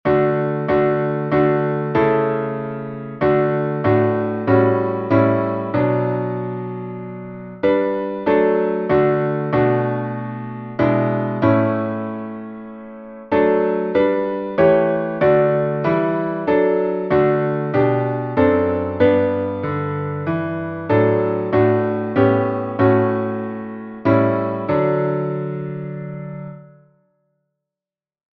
salmo_131B_instrumental.mp3